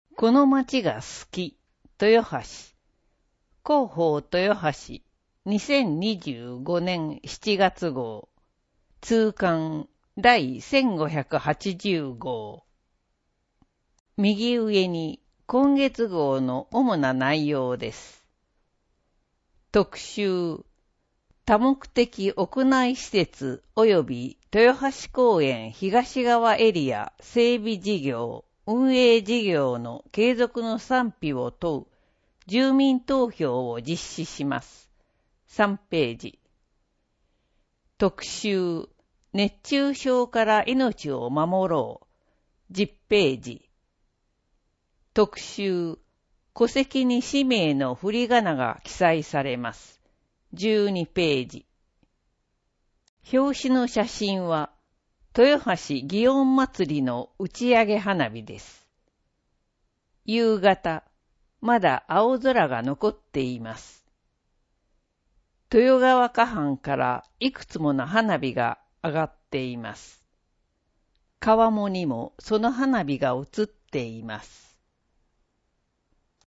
• 「広報とよはし」から一部の記事を音声でご案内しています。視覚障害者向けに一部読み替えています。